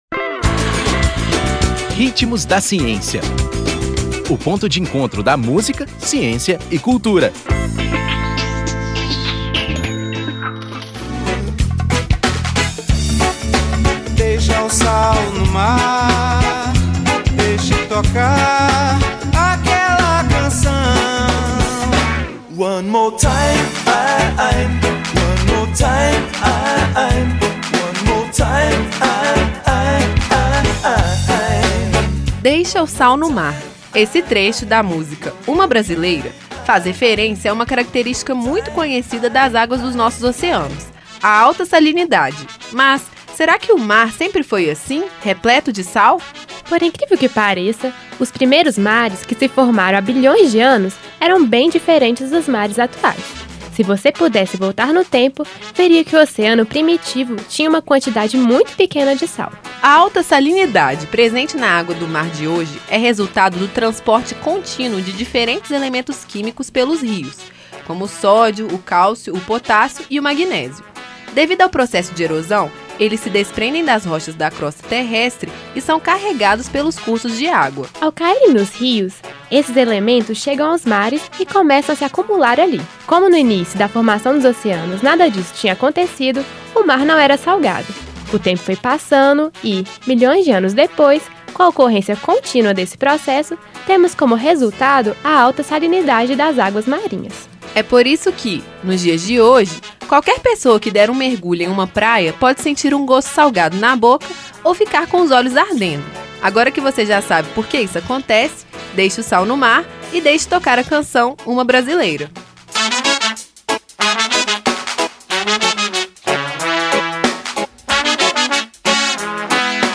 Nome da música: Uma brasileira
Intérprete: Os Paralamas do Sucesso e Djavan